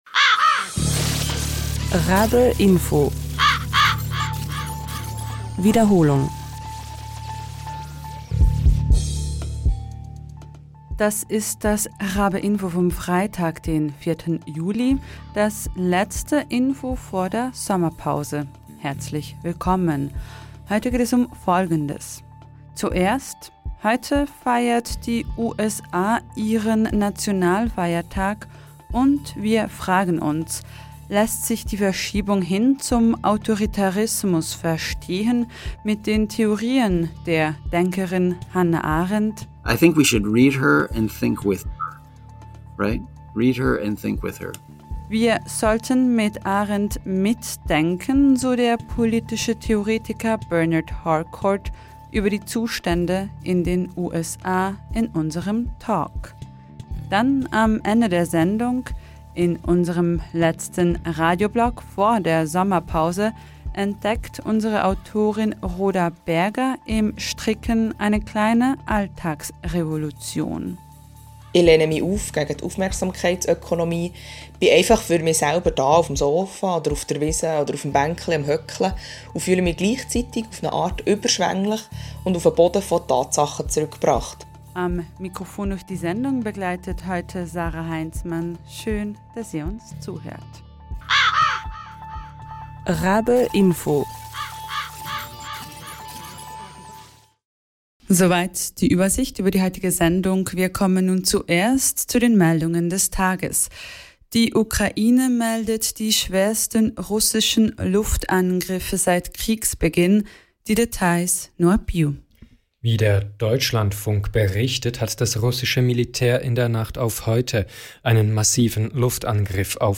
Heute feiert die USA ihren Nationalfeiertag, und wir fragen uns: Lässt sich die Verschiebung hin zum Autoritarismus verstehen mit den Theorien der Denkerin Hannah Arendt? Ja, aber mit Vorbehalt, so der politische Theoretiker Bernard Harcourt im Gespräch über die Zustände in den USA.